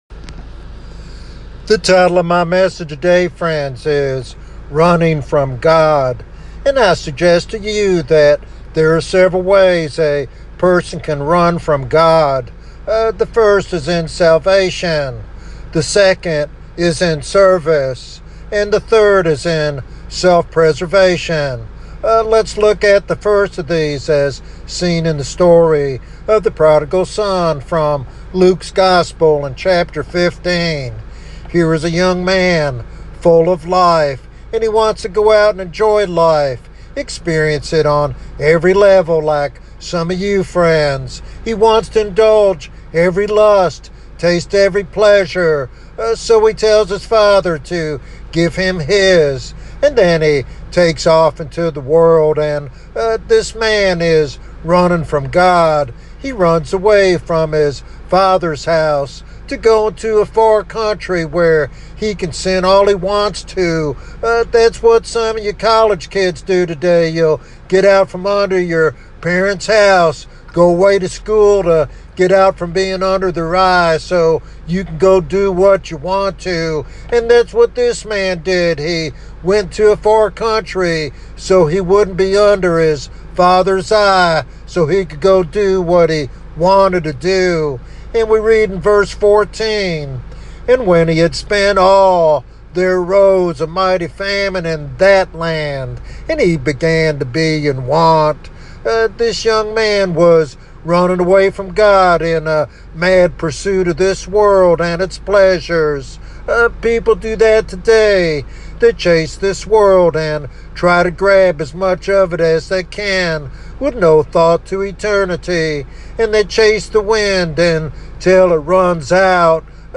In this topical sermon